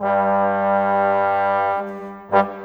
Rock-Pop 07 Trombones _ Tuba 02.wav